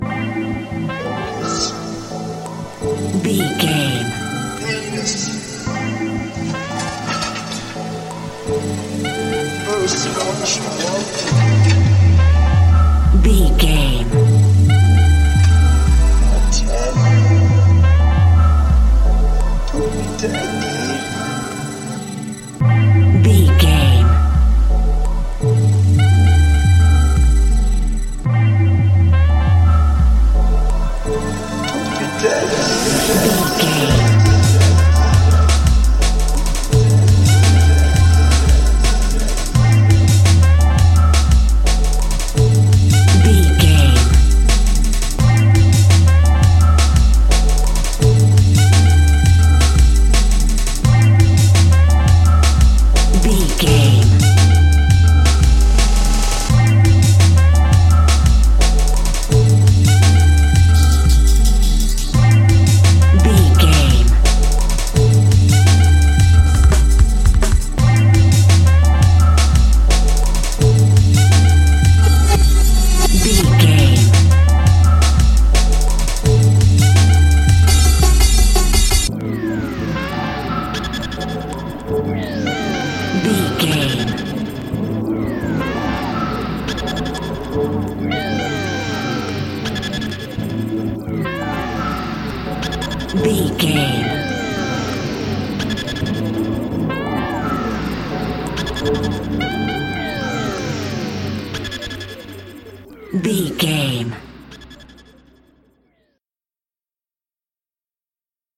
Epic / Action
Aeolian/Minor
drum machine
synthesiser